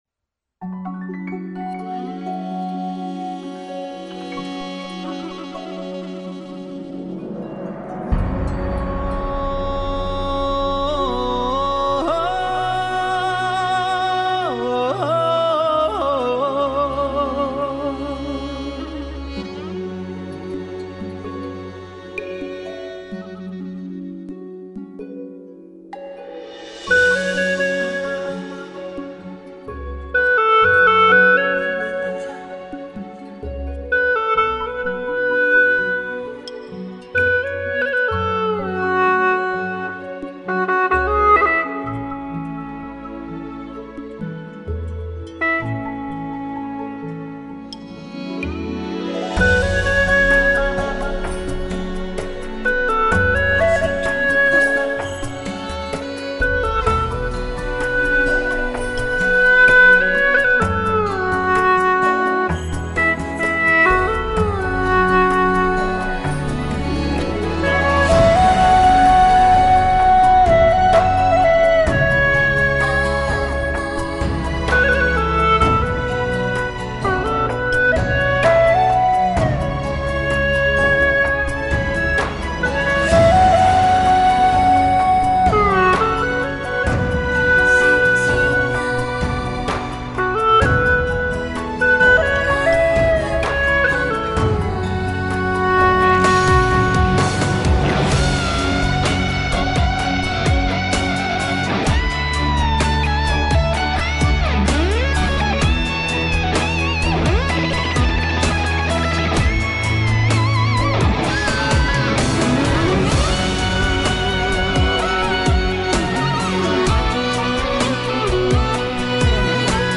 曲类 : 流行